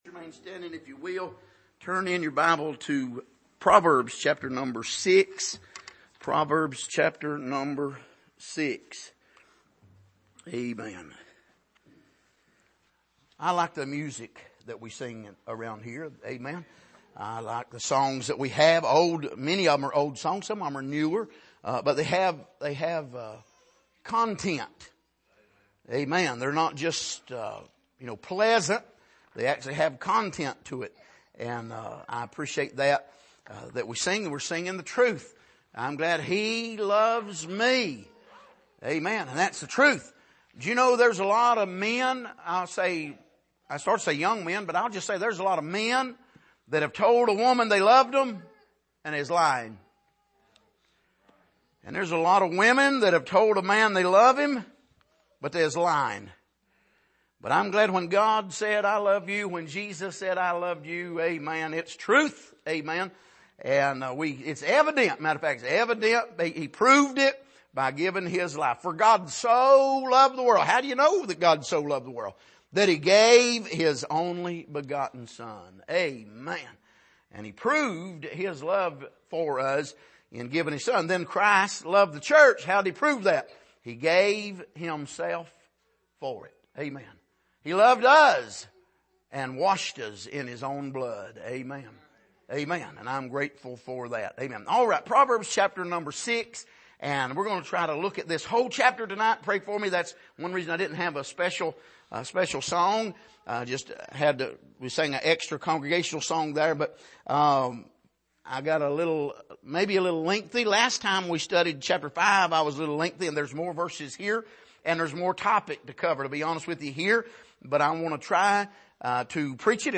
Passage: Proverbs 6:1-35 Service: Sunday Evening